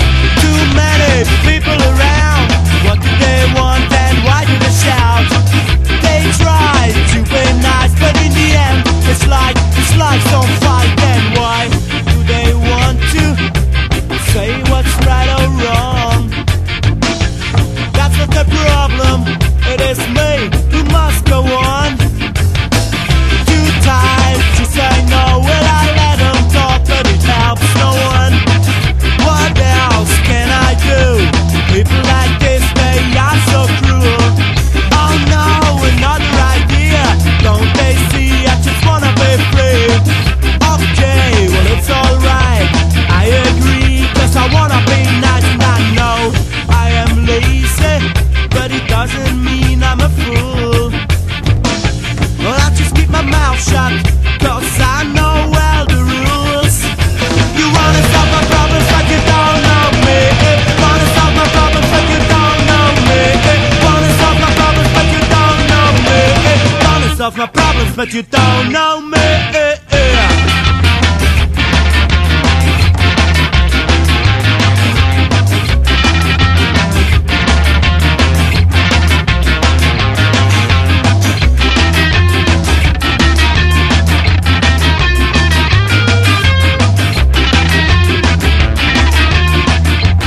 ROCK / PUNK / 80'S～ / GARAGE PUNK (US)